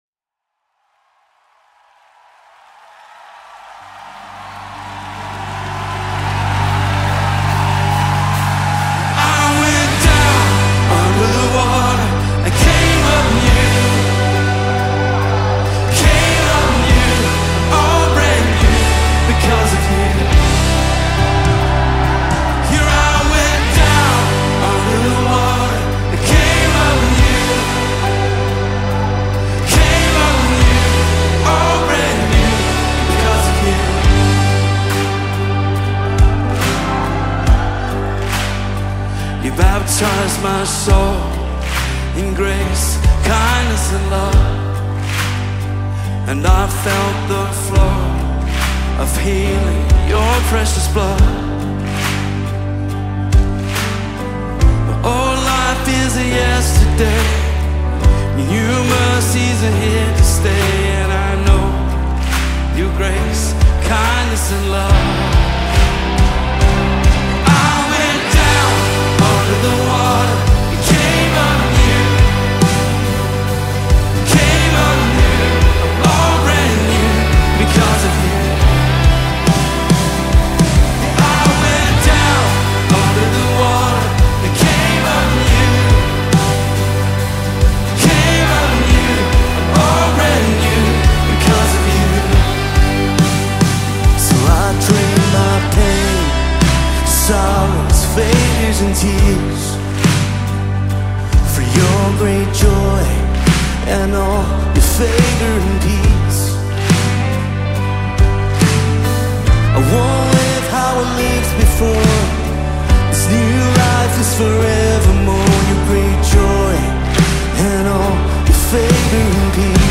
2025 single